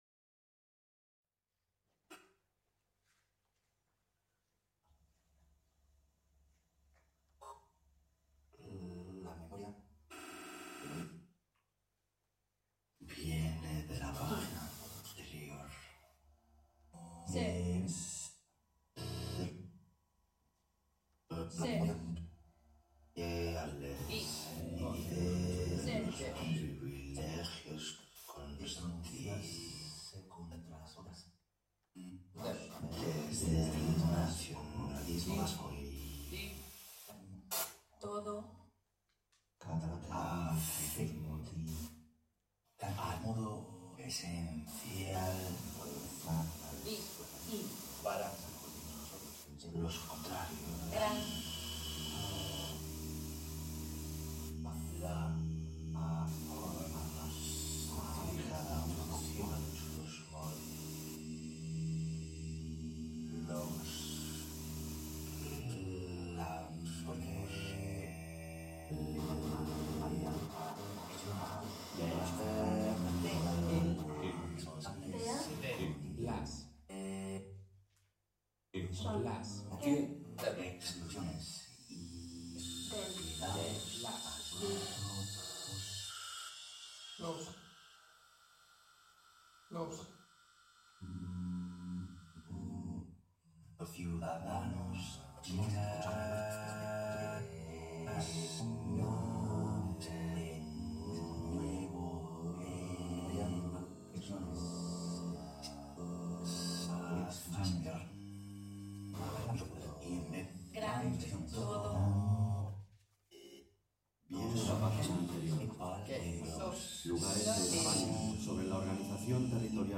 composition for laptops
is performed by 4 musicians in December 2011, on the release concert of Issue 6 of Requited Journal in Enemy